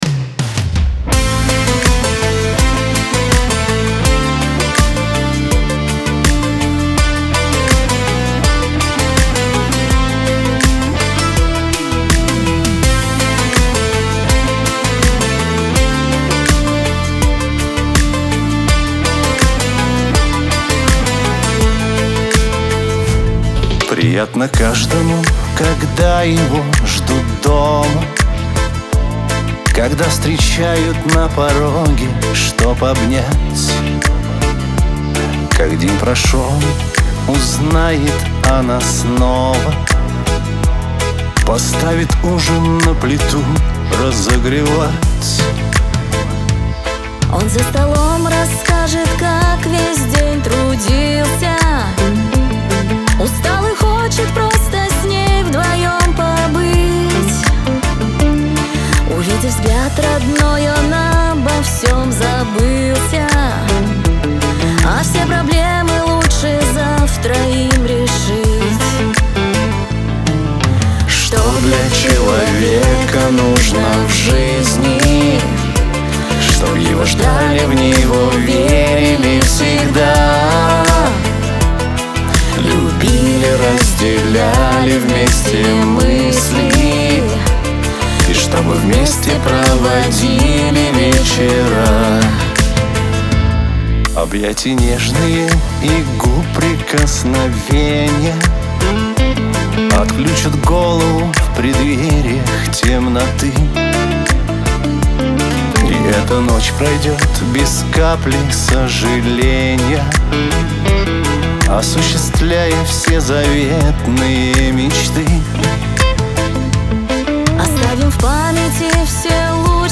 pop , Лирика
дуэт , эстрада